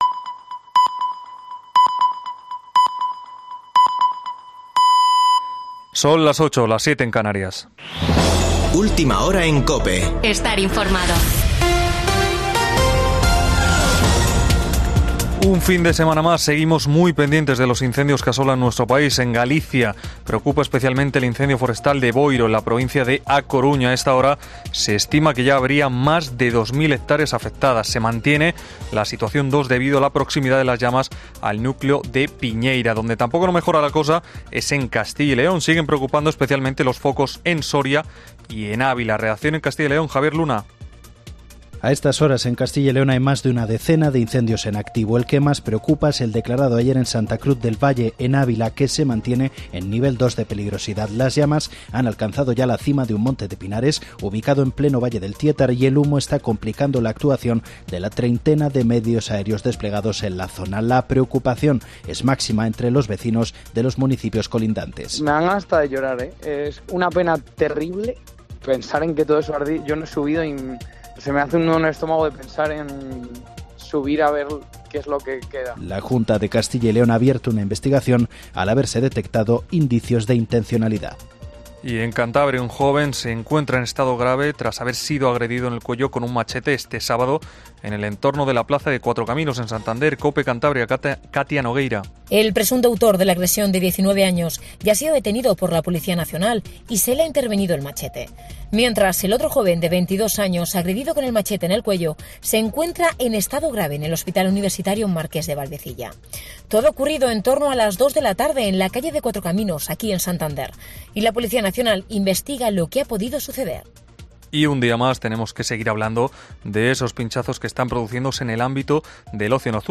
Boletín de noticias de COPE del 6 de agosto de 2022 a las 20.00 horas